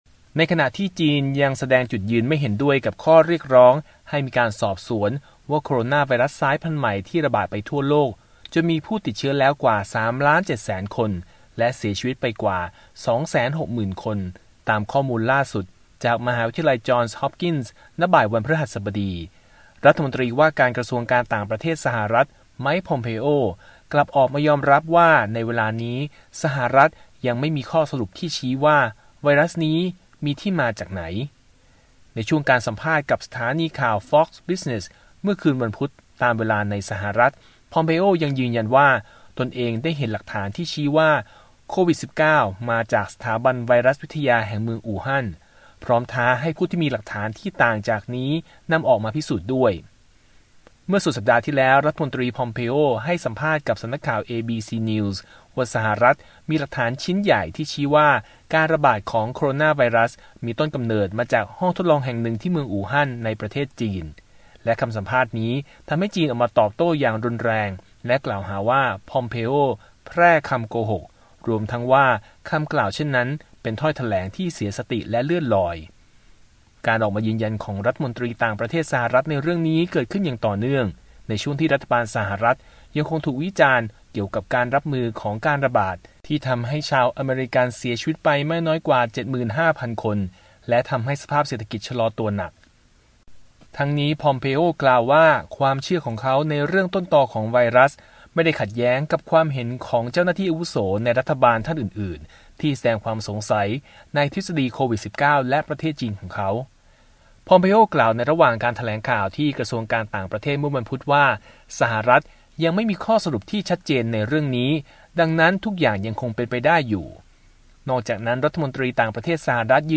U.S. Secretary of State Mike Pompeo speaks about the coronavirus disease (COVID-19) during a media briefing at the State Department in Washington, May 6, 2020.